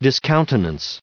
added pronounciation and merriam webster audio
1855_discountenance.ogg